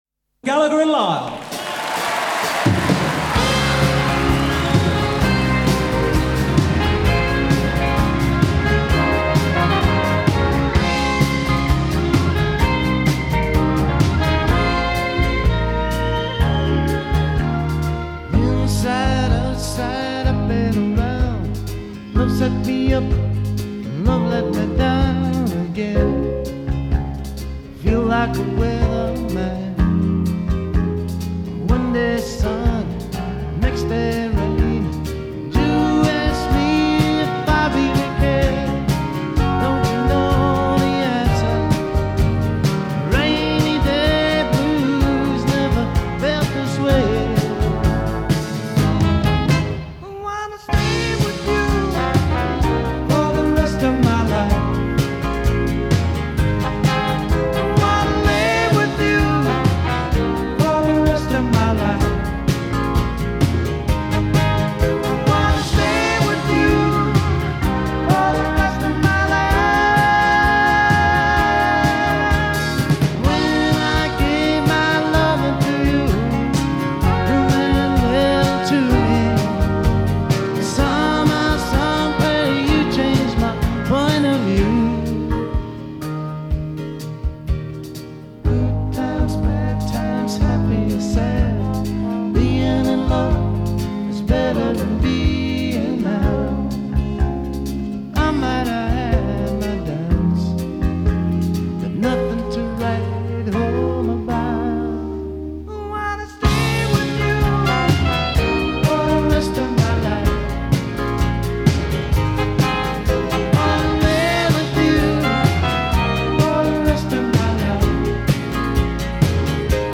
soft rock
un dúo escocés